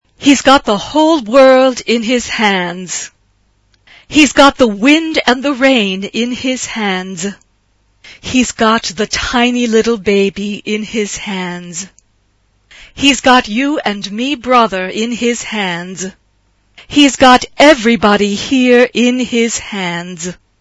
SA (2 voix égale(s) d'enfants) ; Partition complète.
Gospel.
Spiritual Afro-Américain.
Caractère de la pièce : confiant
Instrumentation : Piano (1 partie(s) instrumentale(s))
Tonalité : mi bémol majeur